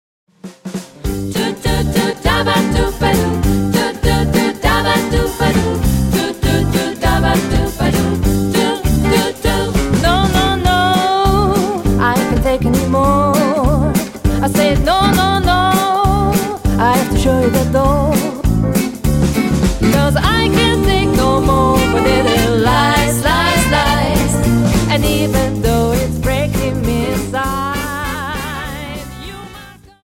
Dance: Quickstep